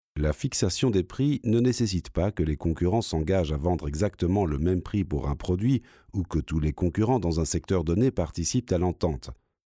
Voix-off pro français grave posée profonde
Sprechprobe: Industrie (Muttersprache):